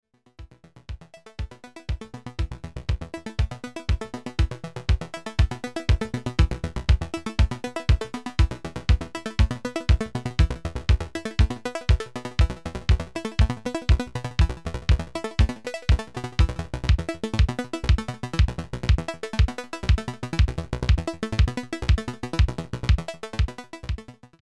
Melodicer auf 4 PPQN eingestellt. 16tel trigger vom Black Sequencer auf Melodicer Clock in und dann langsam Shuffle auf 25% erhöht.
Man hört, wie es sich bei 25% quasi einrastet, aber das klingt dann schon fast so stark wie triplets.